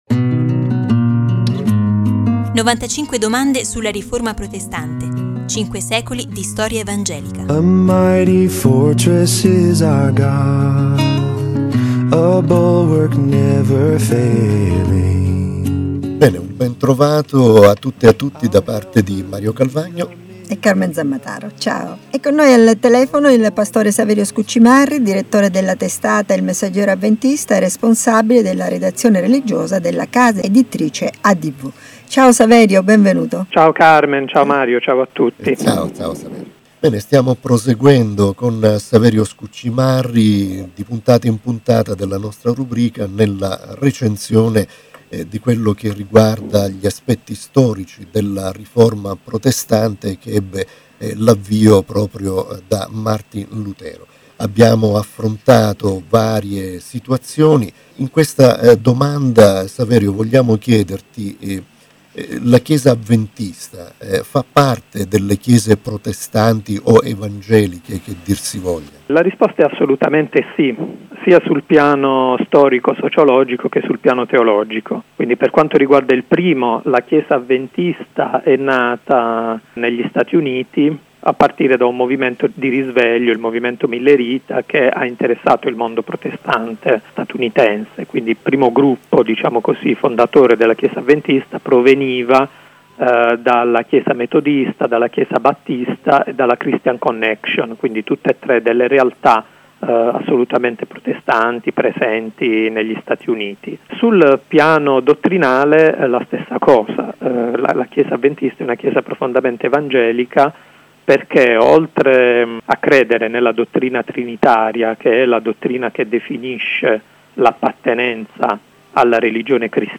intervistano il past.